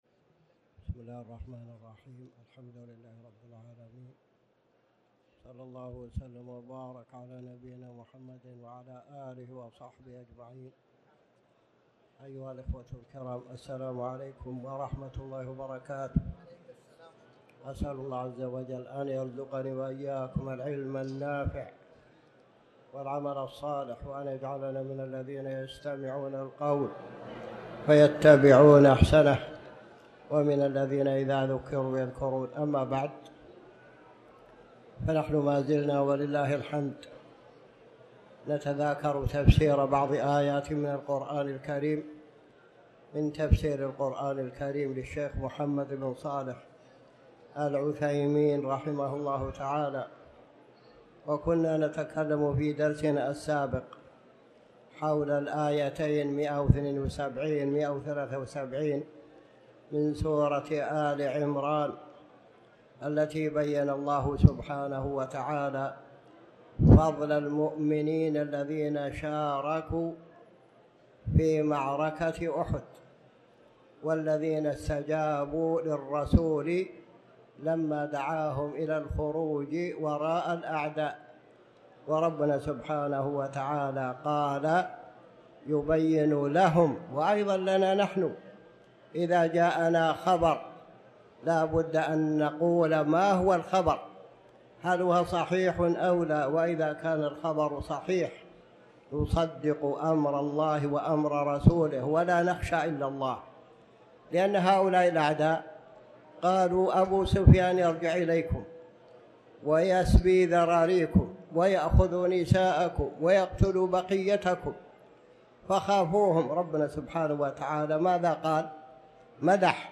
تاريخ النشر ٢٥ ربيع الأول ١٤٤٠ هـ المكان: المسجد الحرام الشيخ